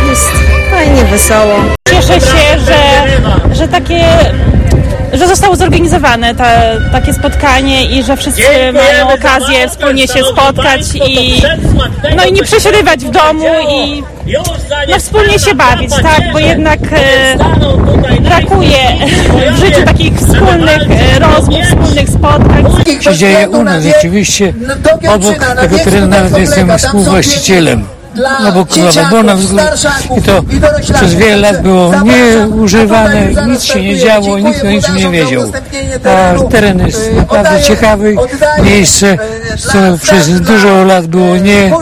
Uczestnicy wydarzenia mówią, że to piękne wydarzenie historyczne, ale i  świetna zabawa.